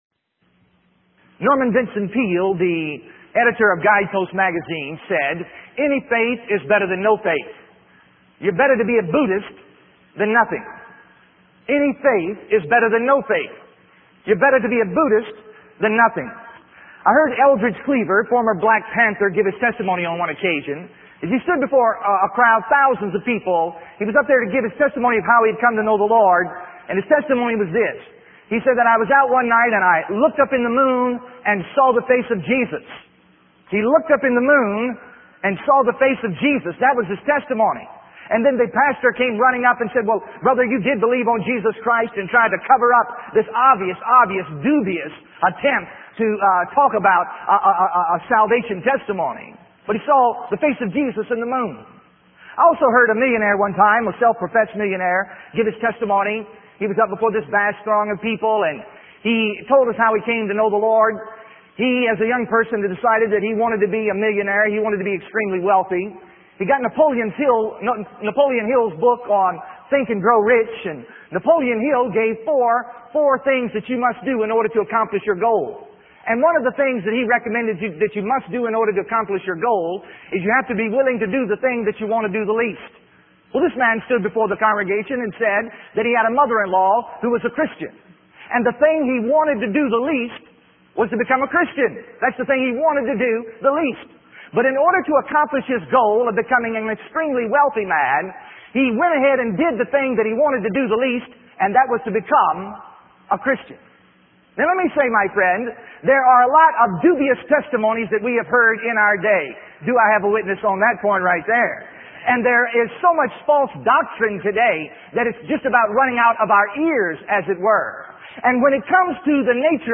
In this sermon, the preacher shares two stories to emphasize the importance of prioritizing our eternal relationship with God. The first story is about a man who desired to become a millionaire and followed the advice of Napoleon Hill's book.